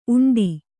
♪ uṇḍi